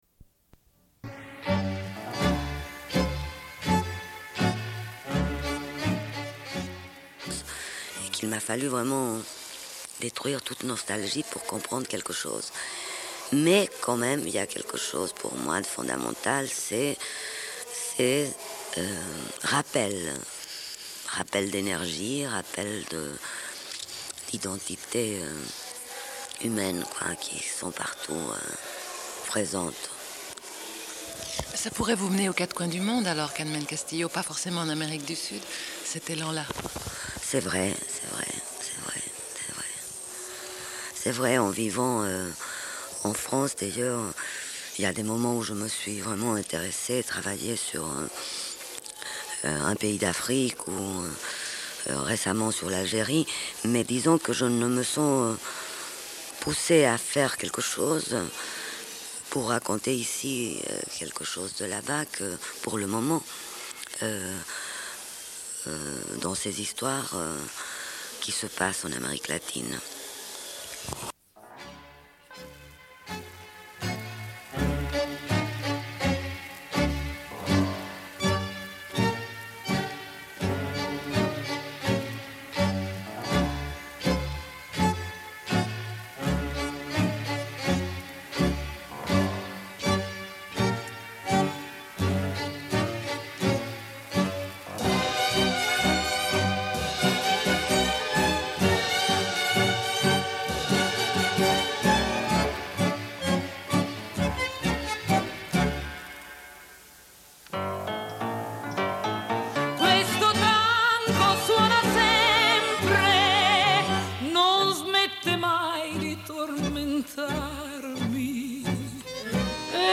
Une cassette audio, face B29:20